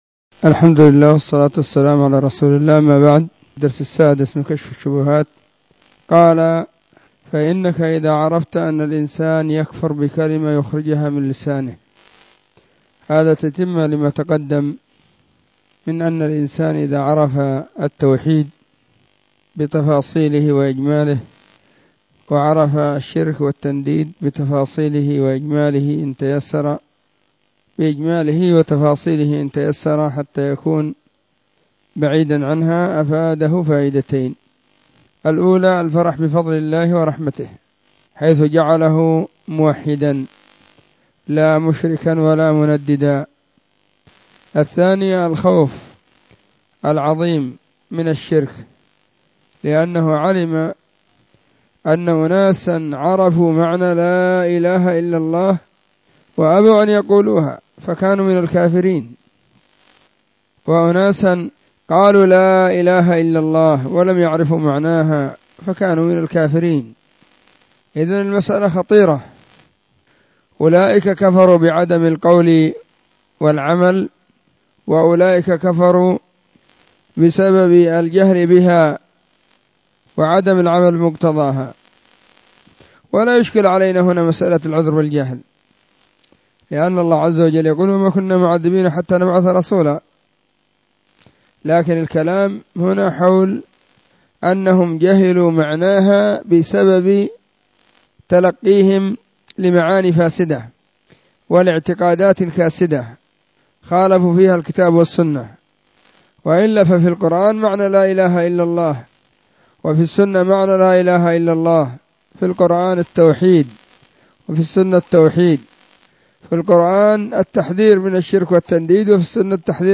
كتاب كشف الشبهات الدرس06
📢 مسجد الصحابة بالغيضة, المهرة، اليمن حرسها الله.